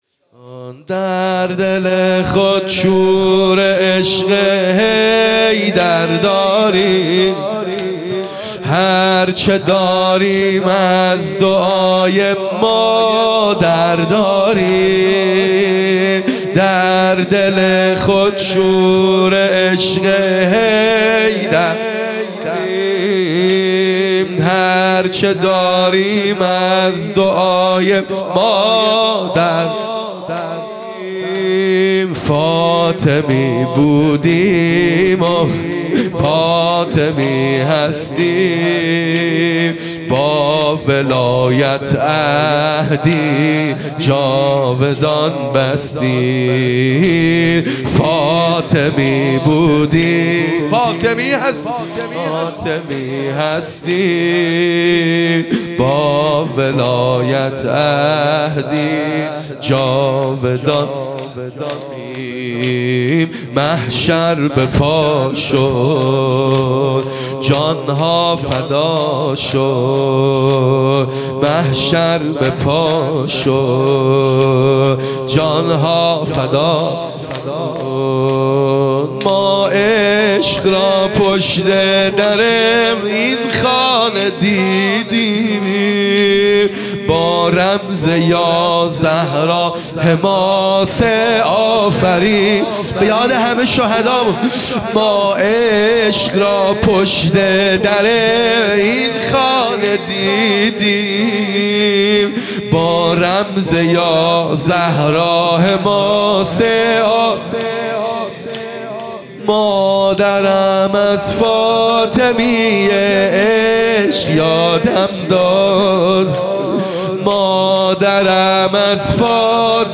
خیمه گاه - هیئت بچه های فاطمه (س) - زمینه | در دل خود شور عشق حیدر داریم
جلسۀ هفتگی